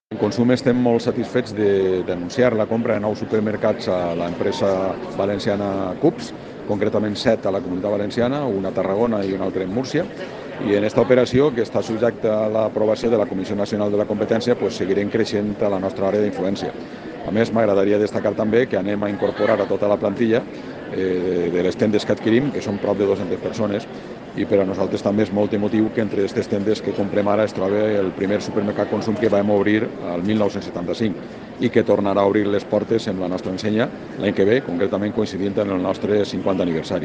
Tall de veu